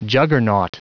Prononciation du mot juggernaut en anglais (fichier audio)
juggernaut.wav